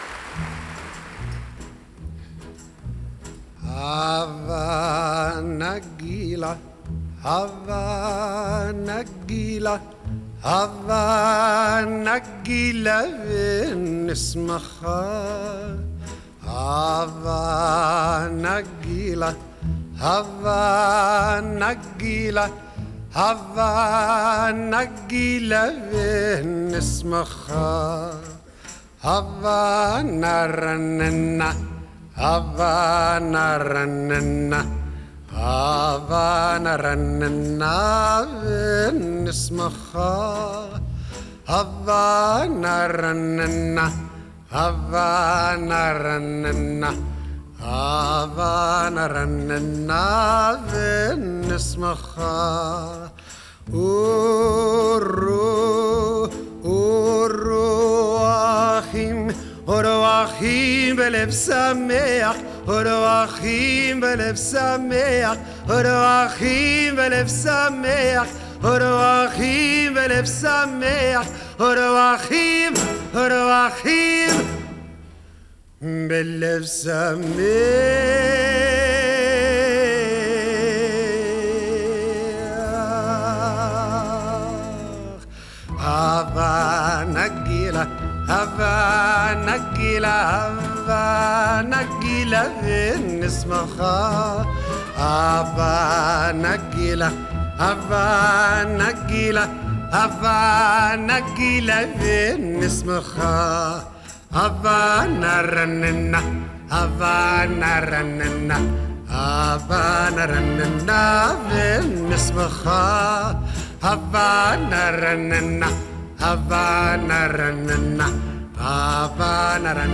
Genre: Folk
Recorded at Carnegie Hall, April 19 and 20, 1959.